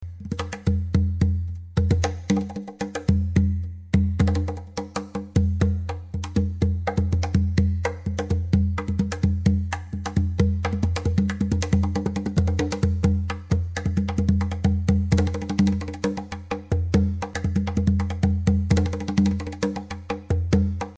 • Studio-quality hand-drums are great for indoor and outdoor use
• Made of dark maple and trimmed with walnut
5212_SoundClip_SlapDrum-1.mp3